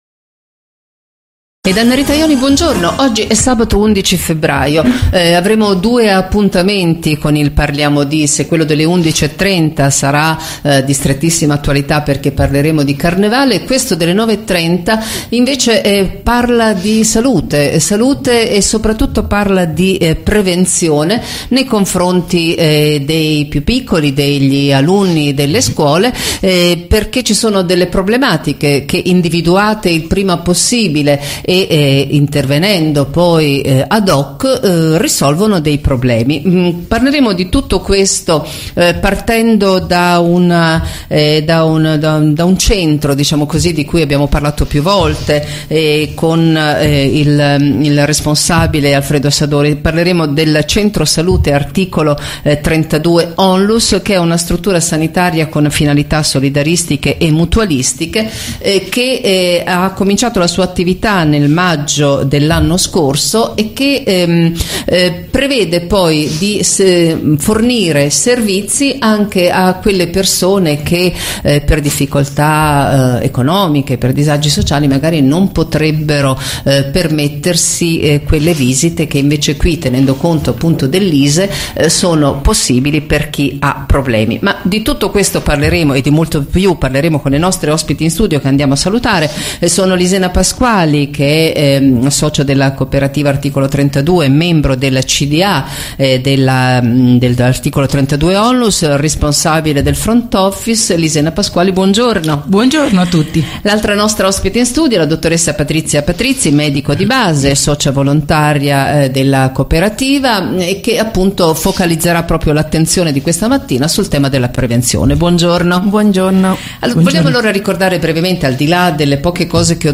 Intervista_radio_fano_11_02.mp3